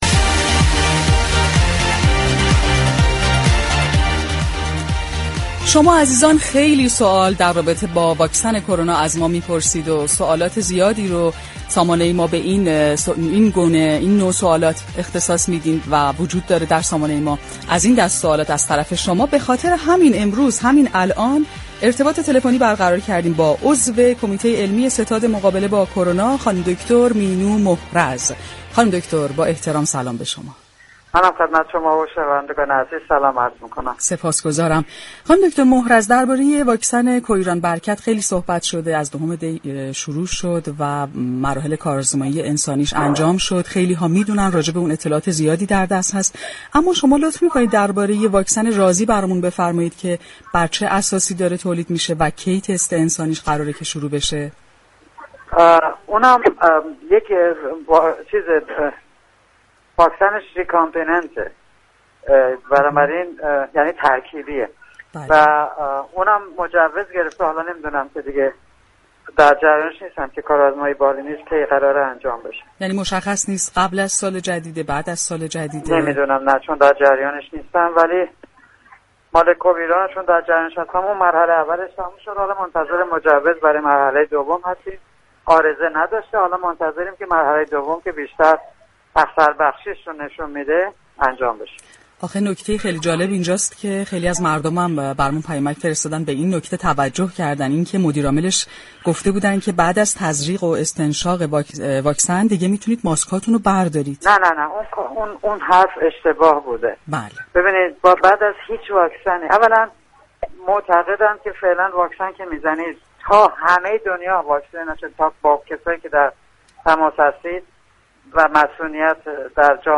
در گفتگو با برنامه «تهران ما سلامت»